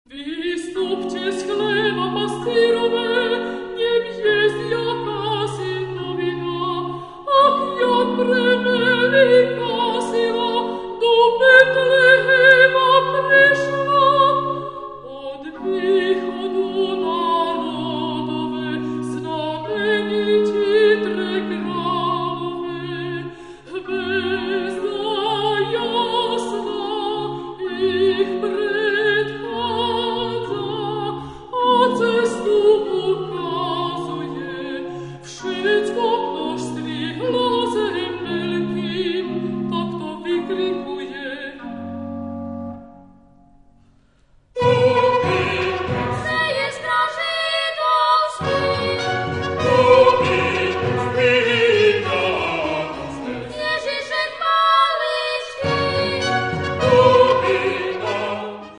His unique pastorales on Slovak and latin lyrics are a good evidence of integration of baroque style and traditional pastoral music.
Offertorium pro Solemnitate Epiphaniae (rec. by. Musica Aeterna, CD Opus 1993)